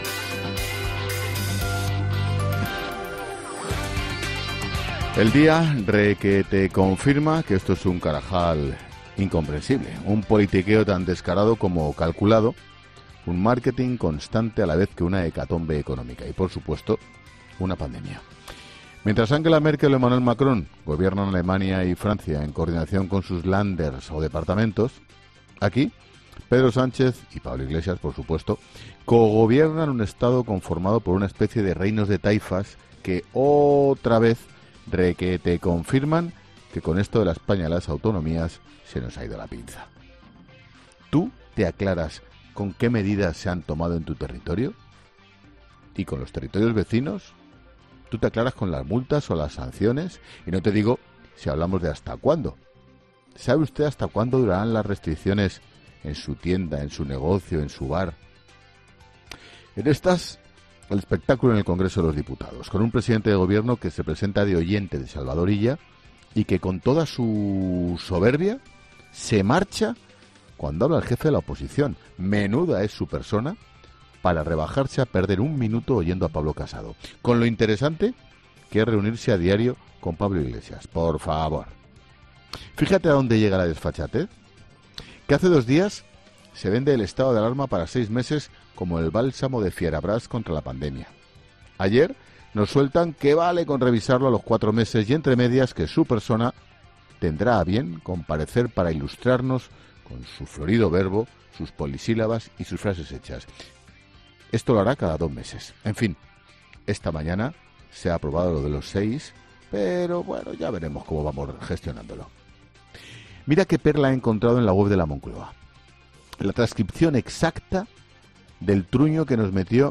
Monólogo de Expósito
El director de 'La Linterna', Ángel Expósito, reflexiona sobre la actualidad nacional e internacional del día